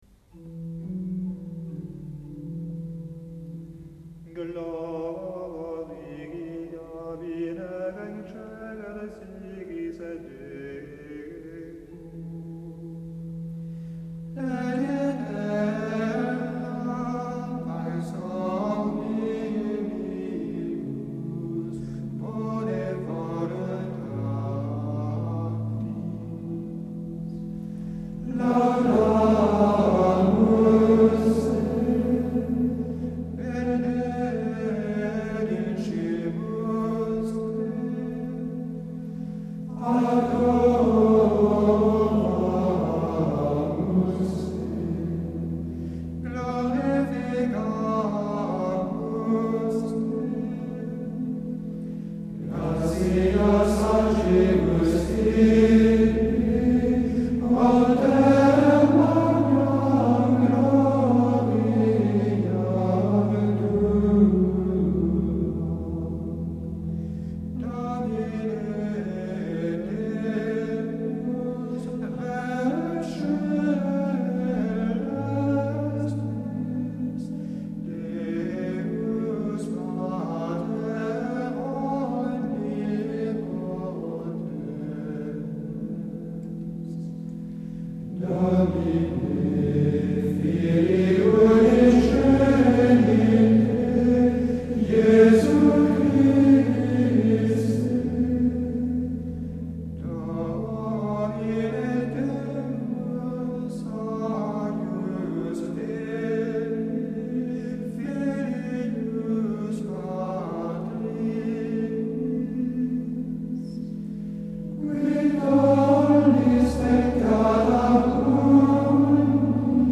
• grégorien noël glória
Il s’agit d’un beau 7e mode, enthousiaste, chaleureux, avec un ambitus bien déployé et des intervalles plutôt vastes et dilatés, ce qui encourage à lui donner une certaine plénitude.
L’intonation est calme, plutôt en 8e mode qu’en 7e : elle s’enroule autour du Sol, tonique du mode, s’appuie sur le Fa, un ton en dessous, puis monte par degrés conjoints Fa-Sol-La-Si-Do, jusqu’au Do, ne dépassant pas encore la quarte et s’arrêtant sur la dominante du 8e mode, sans oser aller jusqu’au Ré, dominante du 7e.
Il est large dans sa belle descente, appuyé, solennel.
Ce Glória si varié demande une richesse vocale, une ampleur de mouvement, dues aux intervalles larges et même un peu spectaculaires.
C’est une mélodie pure et enthousiaste, chaste et lyrique à la fois, qui fait penser au Magnificat de Marie.